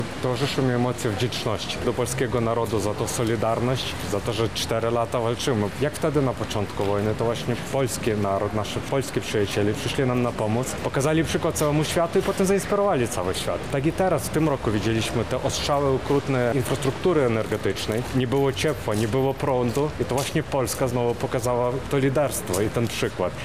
Oleh Kuts– mówi Oleh Kuts, Konsul Generalny Ukrainy w Lublinie.